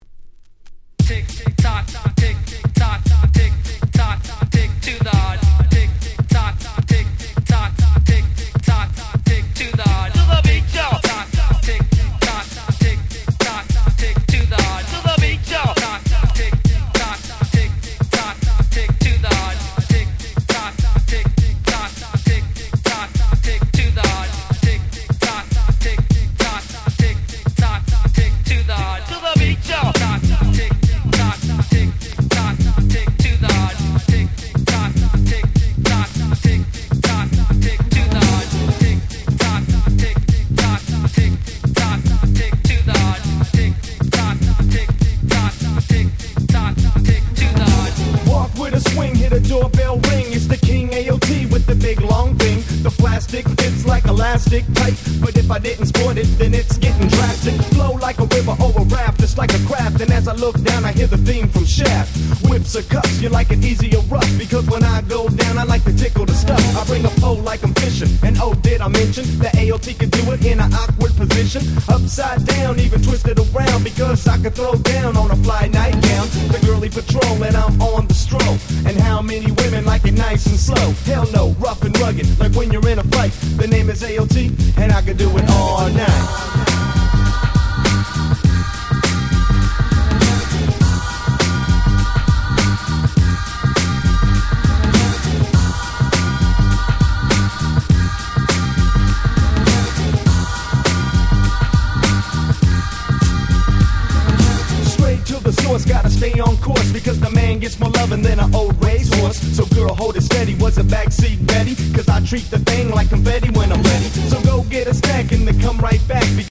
93' Very Nice Cover Rap !!
様々な声ネタが織り込まれた「Hip Hop Mix」が最高！！
しっかりしたBeatも凄く心地良いです！
キャッチー系 90's ウエストコースト G-Rap ウエッサイ Gangsta Rap